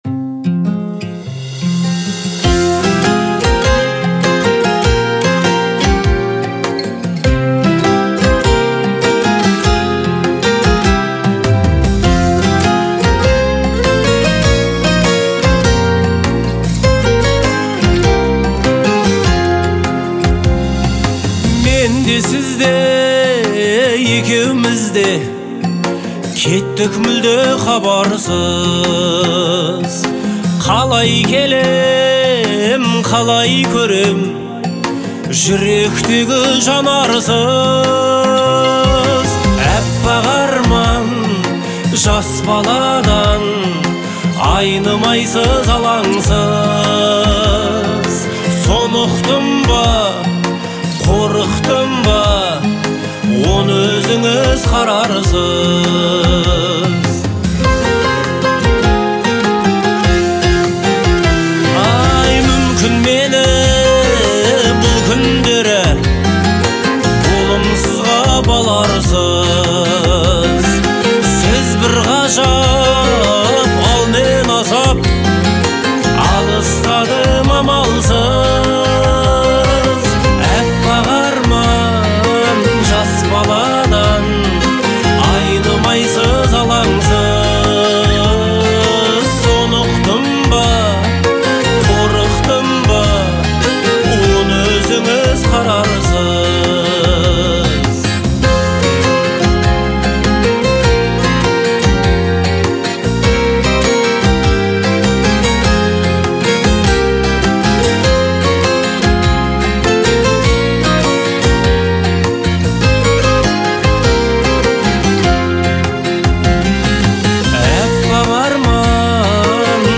это проникновенная композиция в жанре казахской поп-музыки